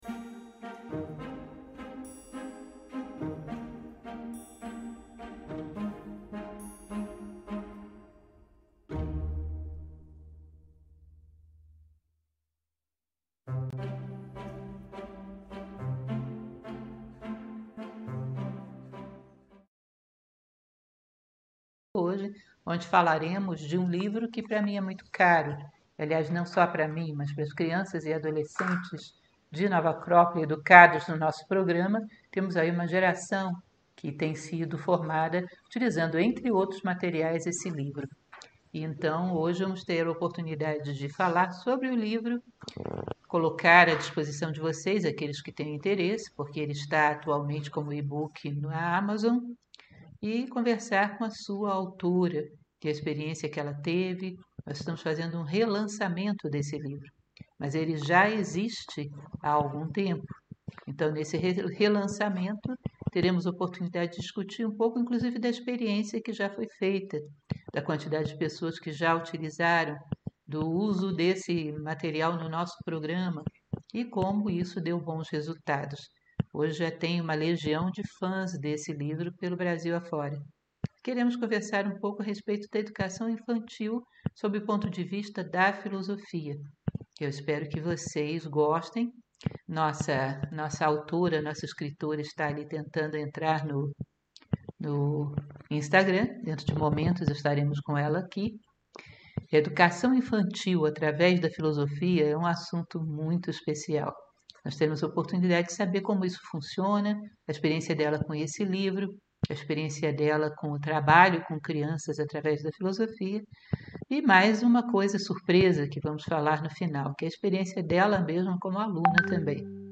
Histórias para Despertar: Filosofia para crianças e adolescentes LIVE